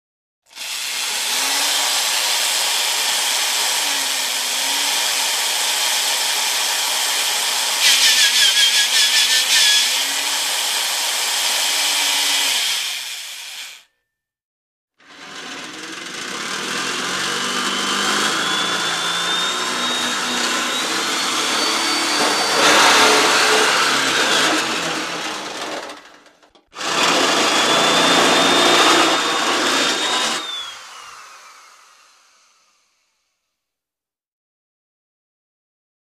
Power Drill | Sneak On The Lot
Power Drill; Spinning Freely, Labors When Hits Wood / Release; Then Drilling Metal, Close Perspective.